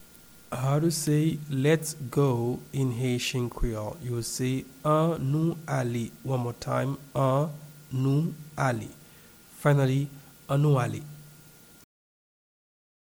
Pronunciation and Transcript:
Lets-go-in-Haitian-Creole-An-nou-ale-pronunciation.mp3